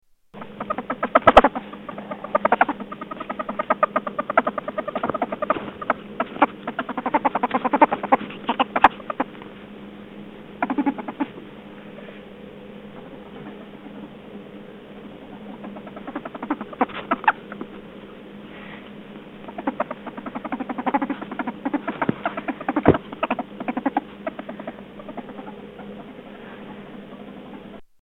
Ferret sound
Tags: Travel Croatia Sounds of Croatia Destination Zagreb Croatia